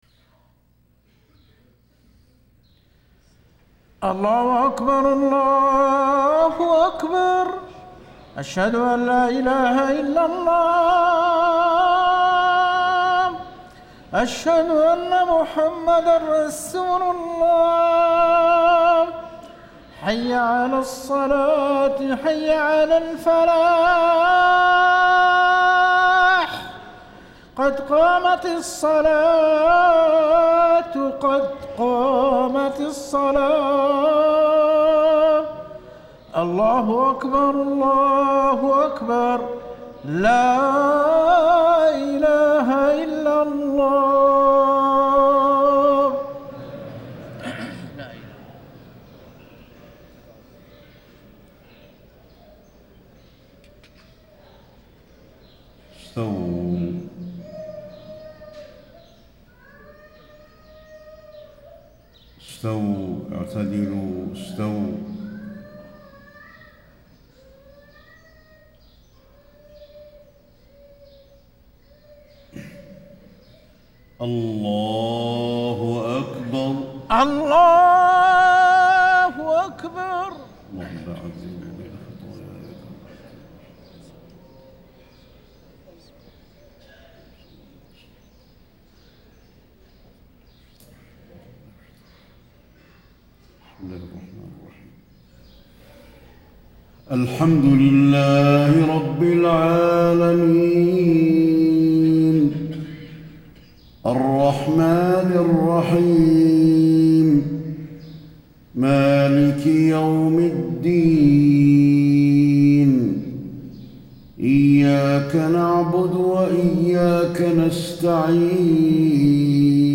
صلاة الفجر5-5-1435 من سورة يس > 1435 🕌 > الفروض - تلاوات الحرمين